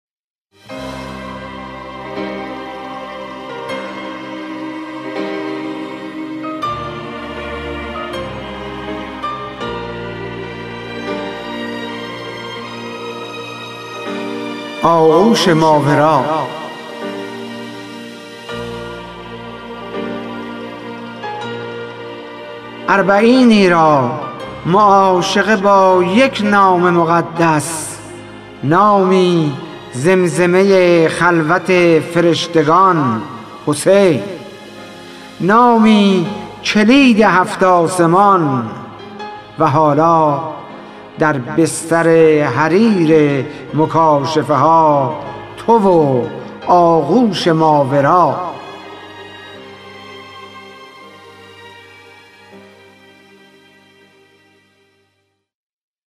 خوانش شعر سپید عاشورایی / ۲